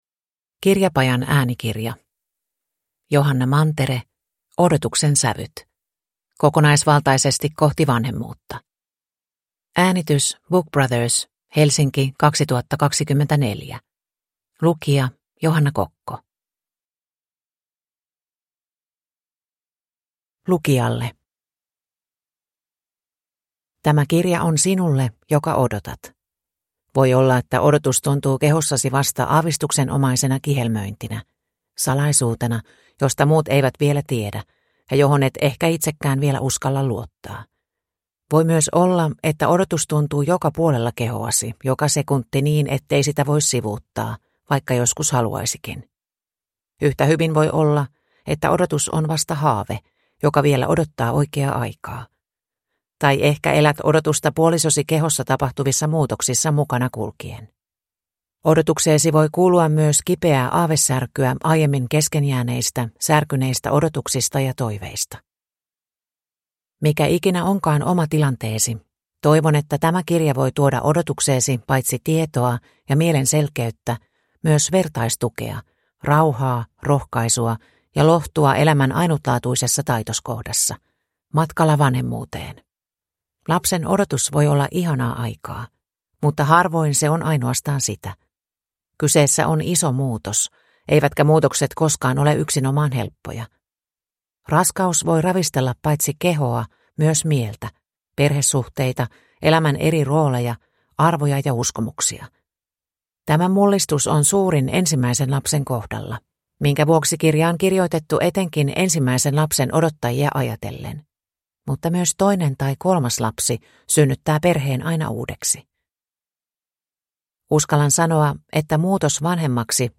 Odotuksen sävyt – Ljudbok